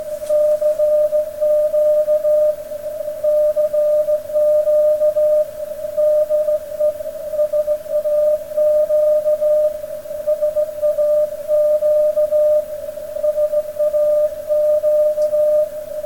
- GigActiv GA3005, Perseus SDR-RX, Versorgung über KFZ-Bordnetz